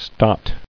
[stot]